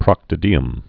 (prŏktə-dēəm)